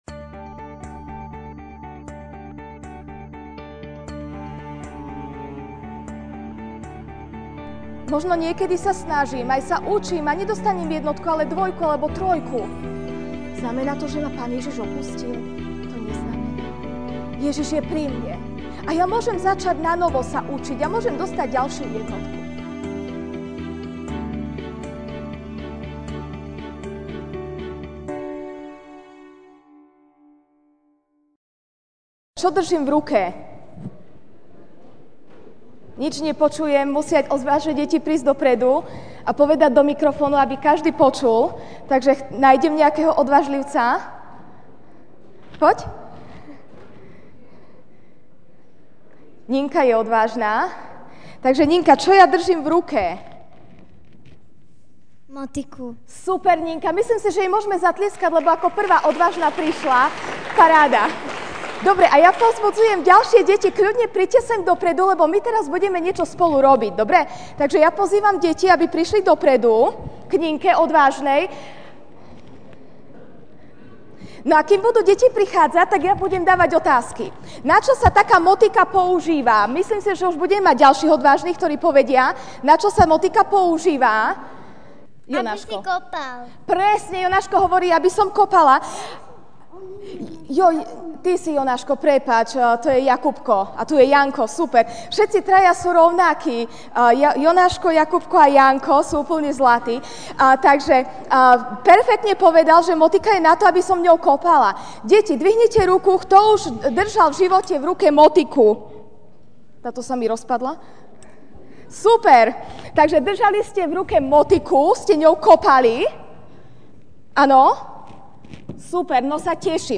Ranná kázeň: Aké je tvoje srdce? (Mt. 13, 3-9) Ajhľa, rozsievač vyšiel rozsievať.